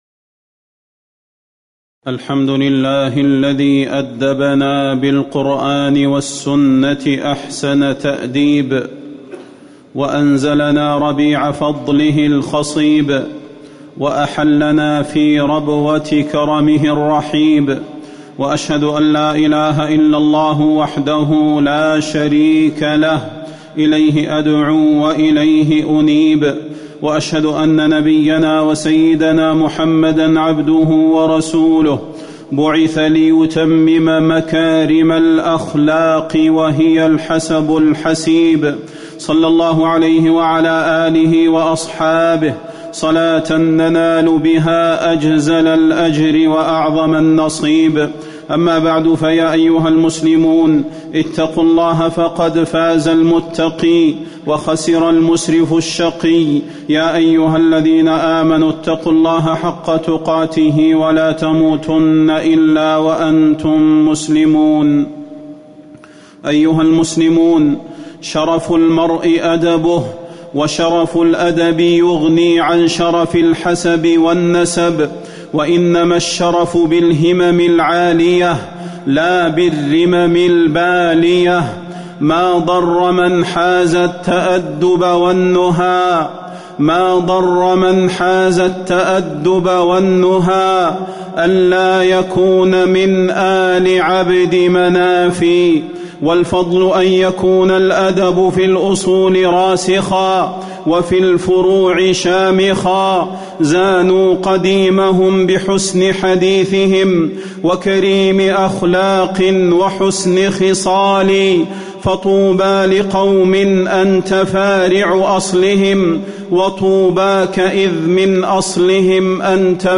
تاريخ النشر ١٤ ربيع الثاني ١٤٤٠ هـ المكان: المسجد النبوي الشيخ: فضيلة الشيخ د. صلاح بن محمد البدير فضيلة الشيخ د. صلاح بن محمد البدير حسن الأدب